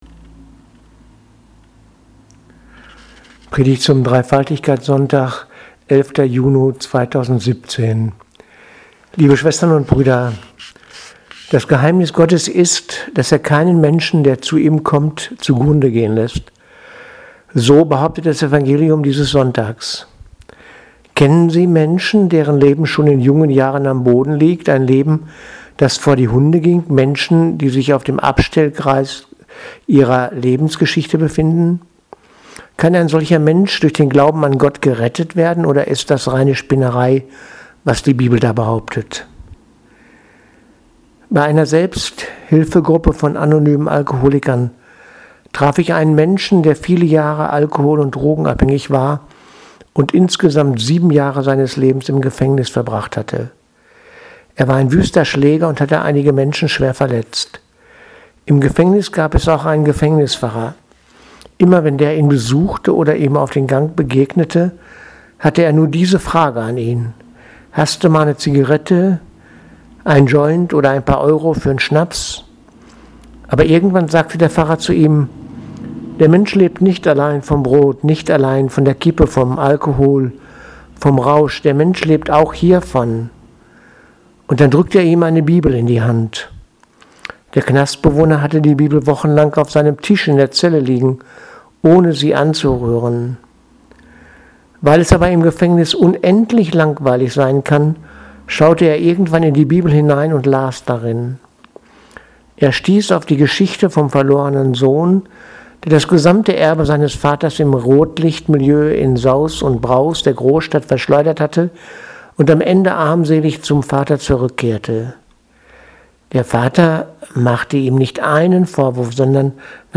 Predigt vom 11.6.2017 – Dreifaltigkeitssonntag